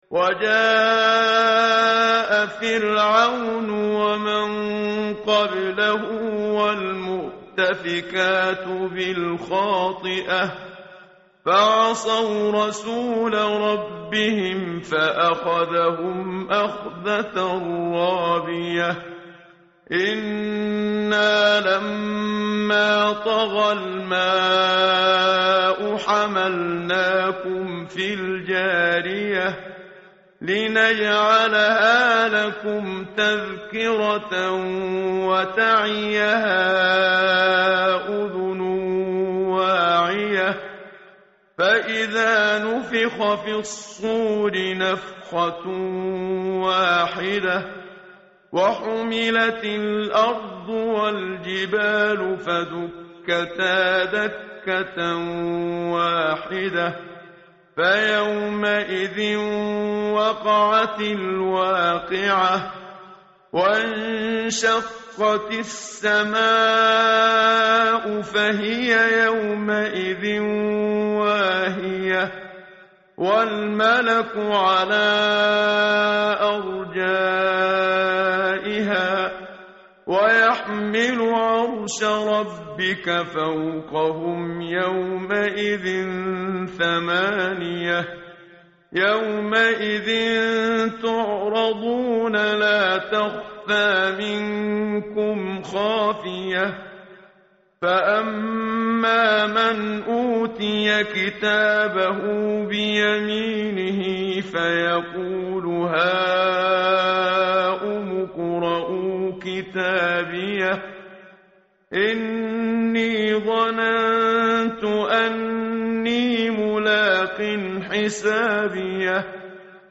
متن قرآن همراه باتلاوت قرآن و ترجمه
tartil_menshavi_page_567.mp3